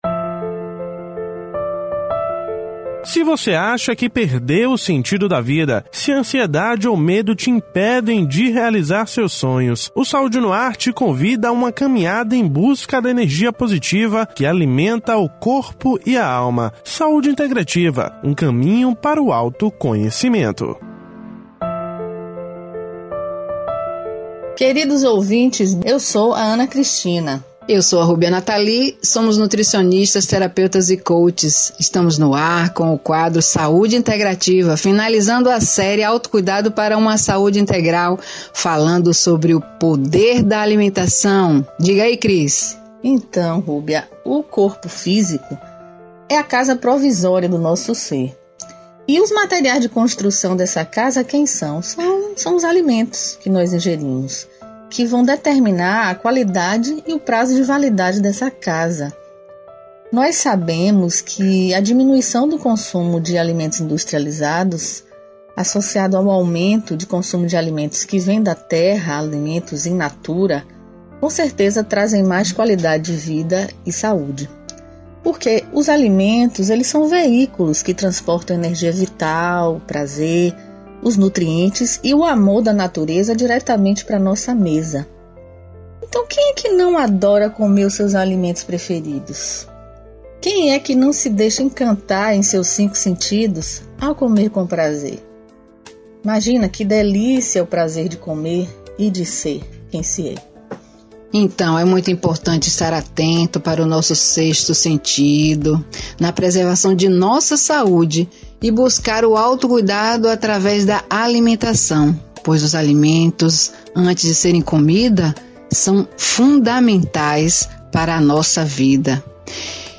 O quadro é exibido toda terça-feira no Programa Saúde No Ar veiculado pela Rede Excelsior de Comunicação: AM 840, FM 106.01, Recôncavo AM 1460 e Rádio Saúde no ar / Web.